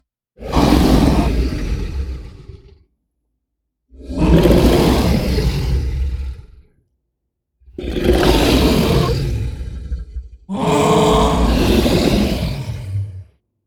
Dragon Hurt Sound
horror